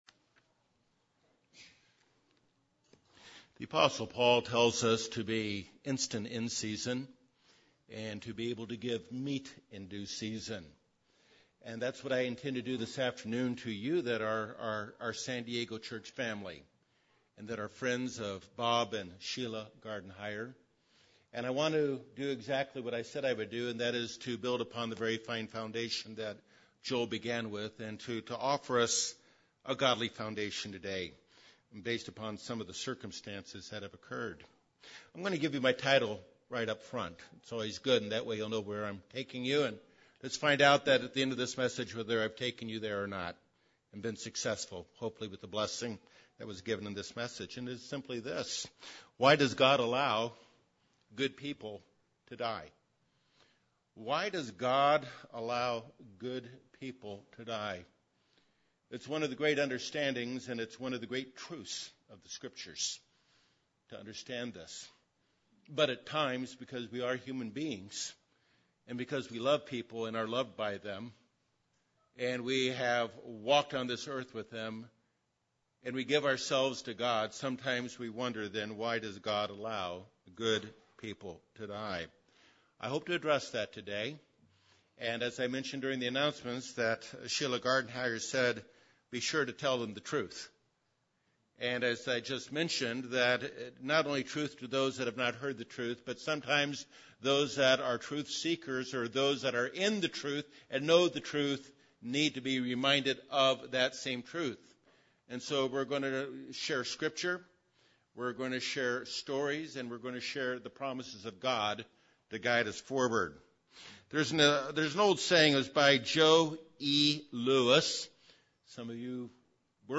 A sad, but comforting and encouraging message.